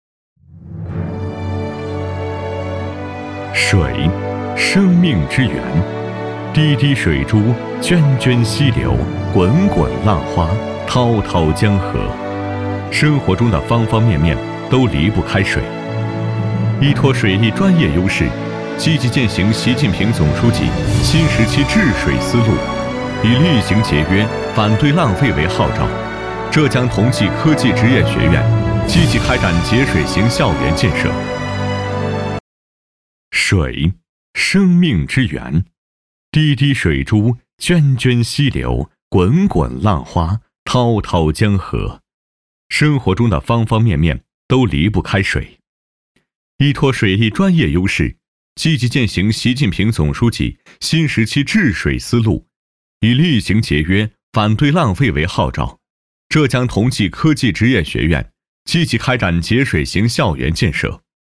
中文男声
• 宣传片
• 大气
• 沉稳